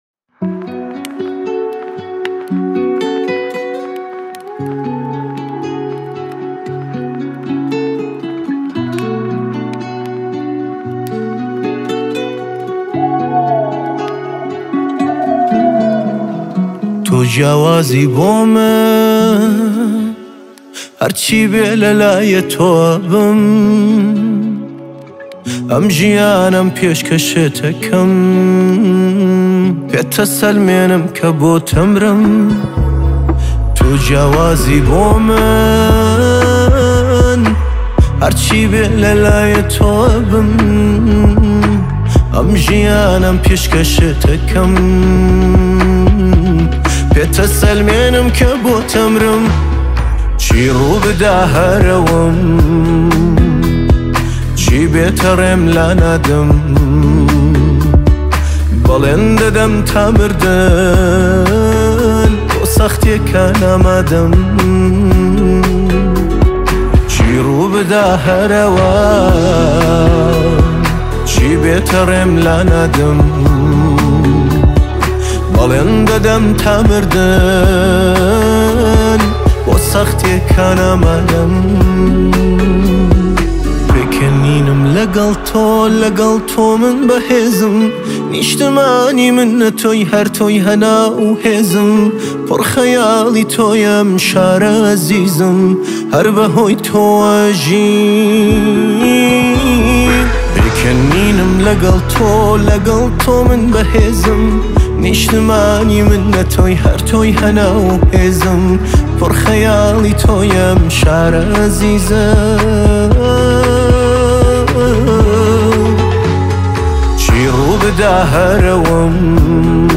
آهنگ کوردی
آهنگ با صدای زن